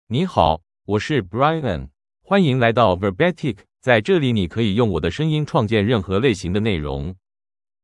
MaleChinese (Mandarin, Traditional)
Brian — Male Chinese AI voice
Voice sample
Male
Brian delivers clear pronunciation with authentic Mandarin, Traditional Chinese intonation, making your content sound professionally produced.